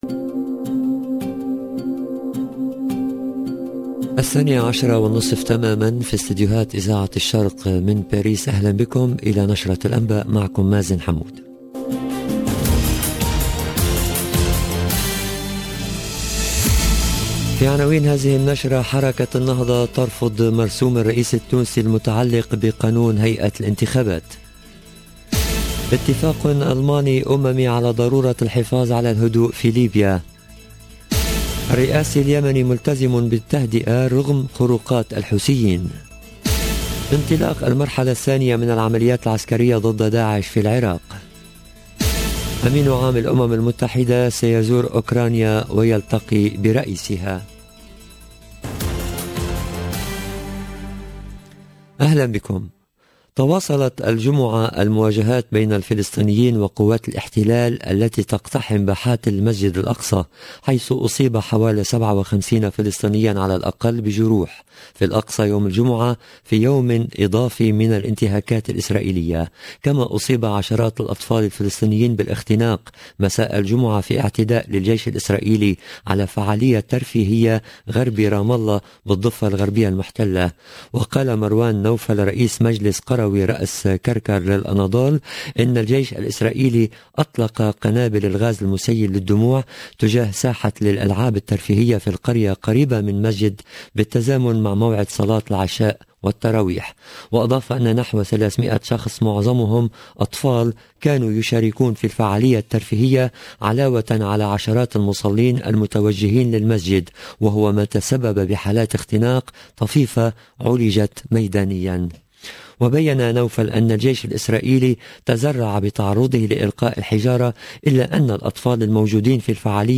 LE JOURNAL DE MIDI 30 EN LANGUE ARABE DU 23/04/22